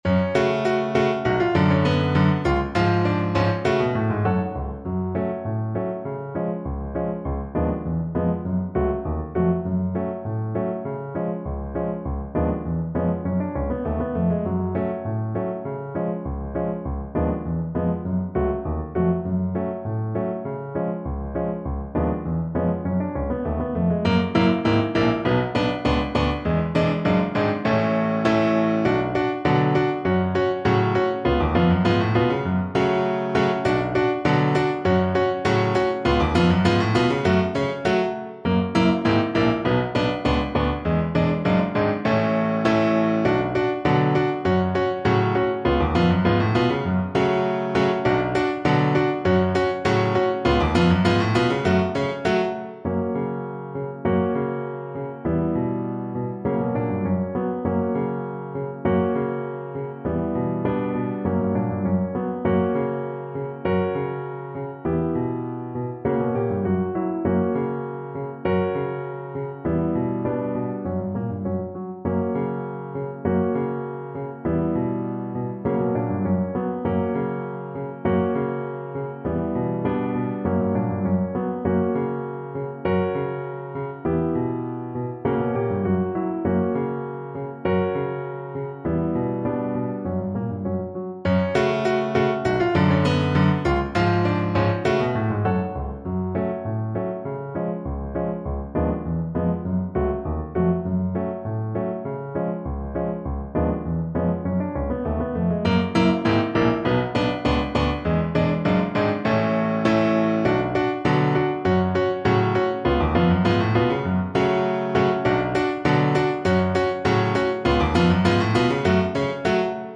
Violin
F# minor (Sounding Pitch) (View more F# minor Music for Violin )
2/4 (View more 2/4 Music)
Eastern European for Violin
Greek